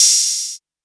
Closed Hats
VOLO Ammo Crate hat 3.wav